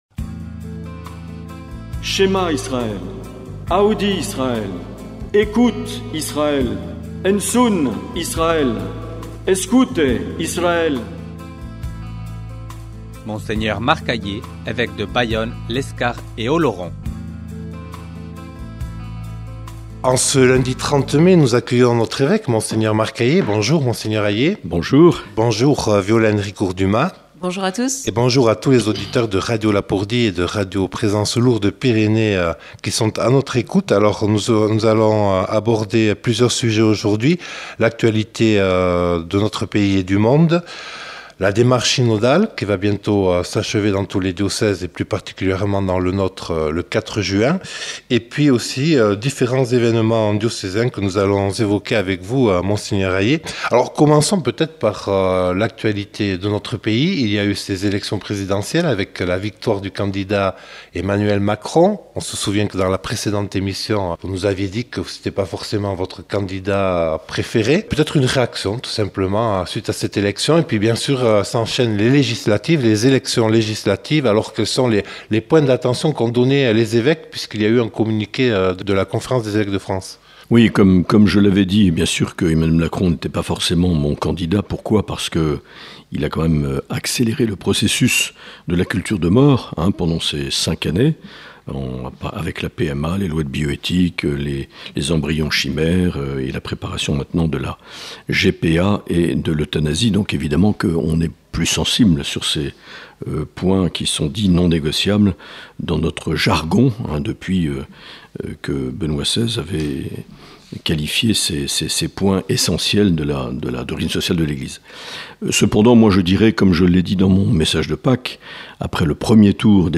Dans cet entretien réalisé le 30 mai, notre évêque revient sur les élections et la guerre en Ukraine, évoque la clôture de la démarche synodale le 4 juin et nous parle de quelques évènements diocésains.